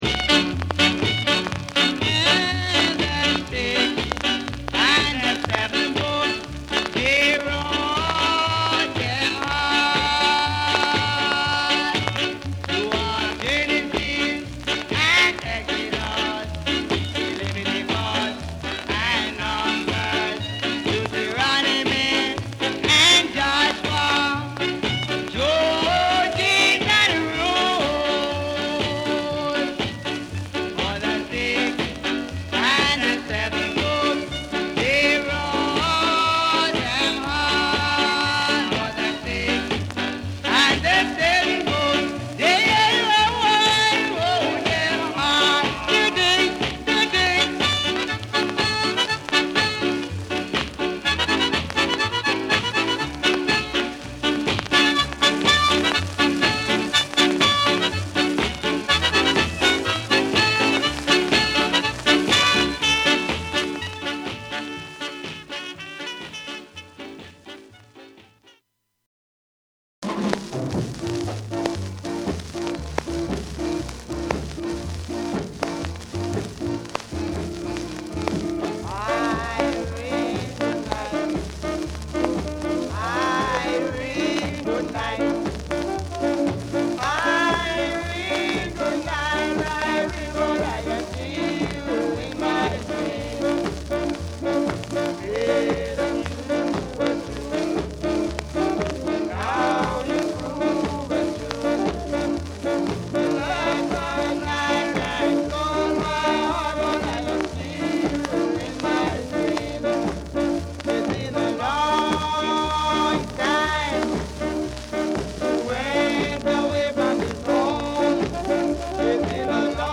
Genre: Ska
疾走感あふれるホーンが炸裂する、ダンス・アンセムとしての熱量を今なお失わない一曲です。